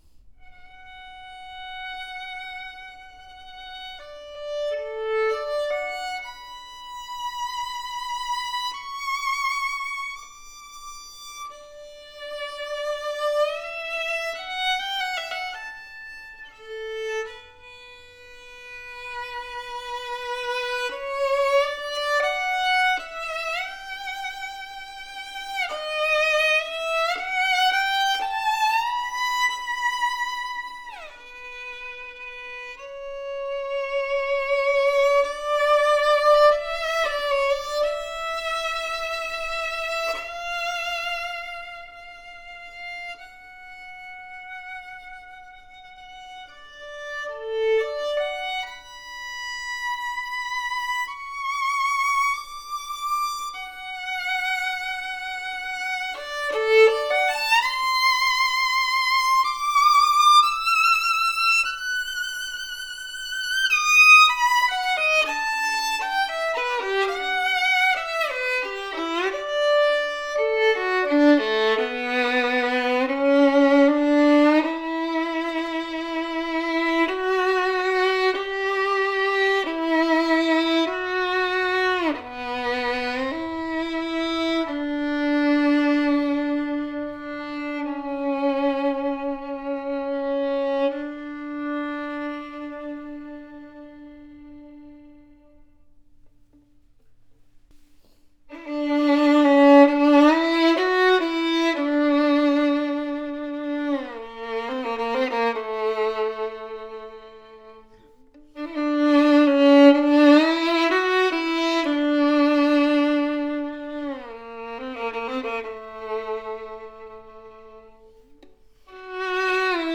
A superior “Cannone” Guarneri copy with POWERFUL and projective tone, one piece flame maple, extremely rare at this price range!
Ringing higher register that projects well and not overly bright, open and pleasant to hear. Huge and resonant G string with a deep open tone.